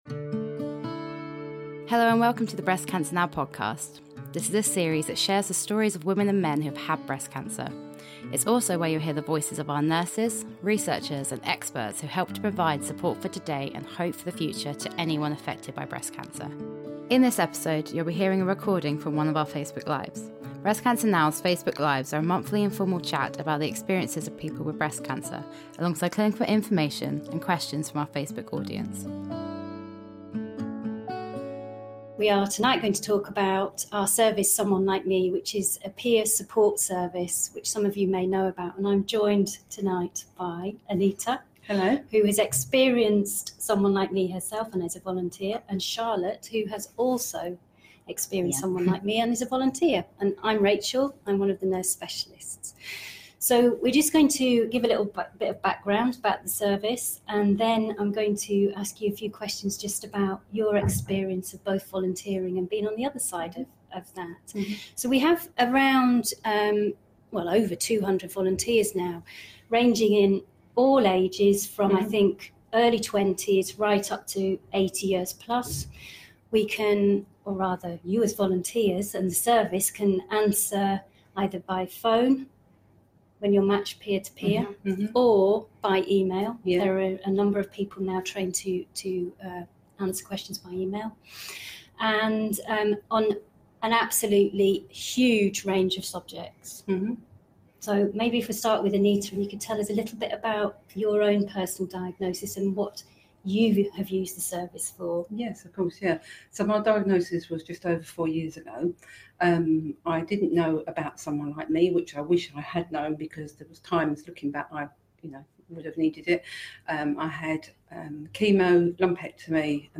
Discussion: Someone Like Me and sharing experiences